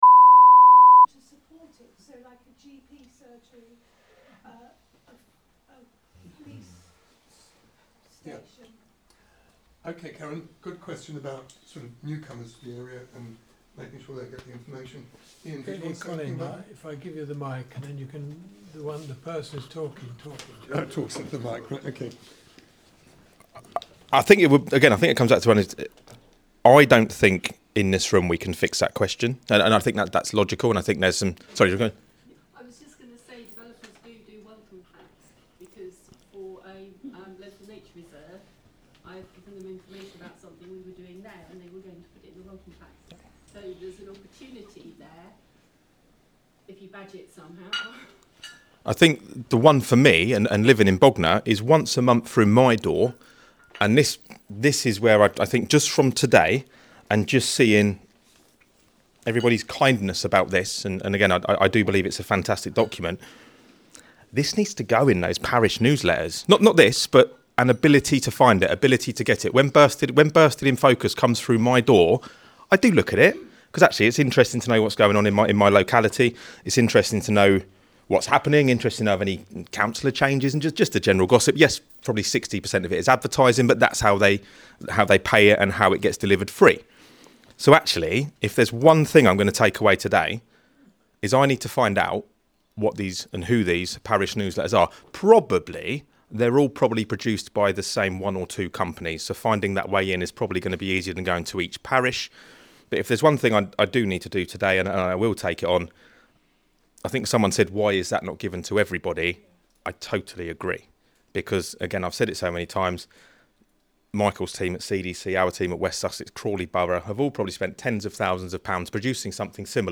Wednesday 4th March, Friends Meeting House, Priory Road, Chichester